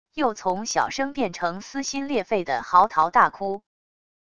又从小声变成撕心裂肺的嚎啕大哭wav音频